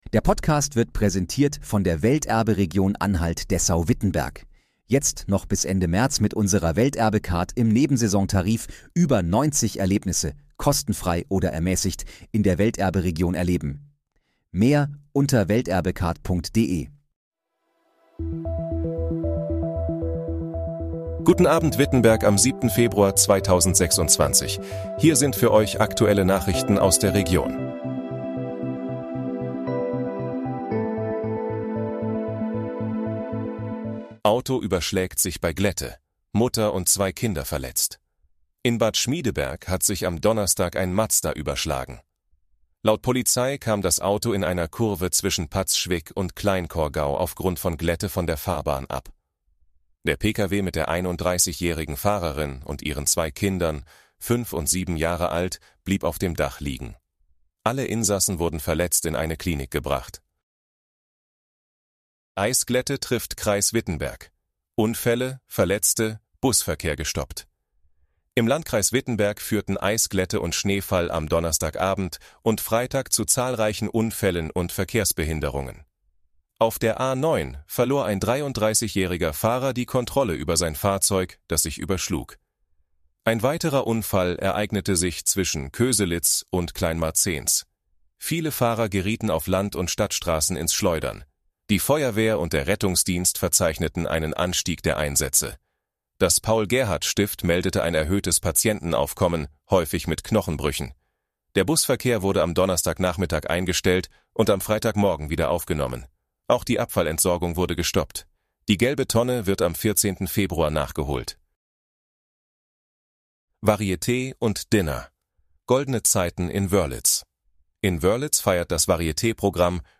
Guten Abend, Wittenberg: Aktuelle Nachrichten vom 07.02.2026, erstellt mit KI-Unterstützung
Nachrichten